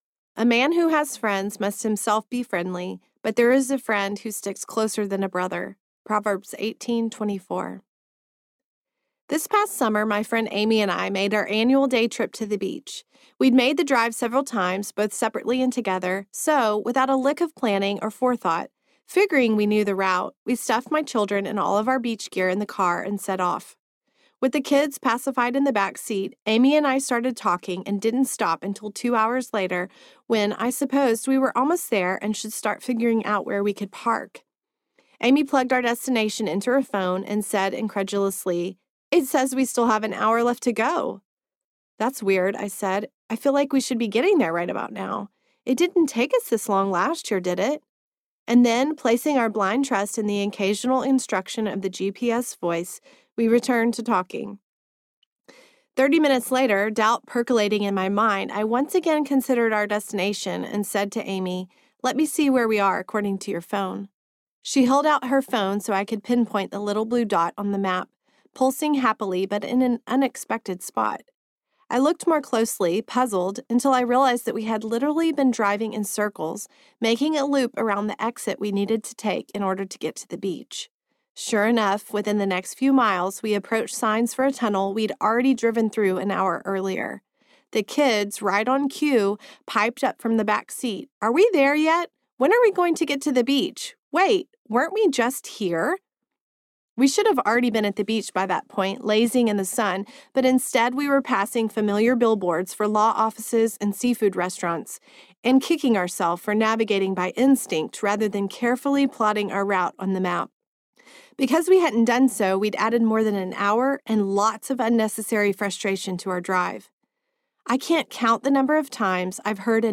Messy Beautiful Friendship Audiobook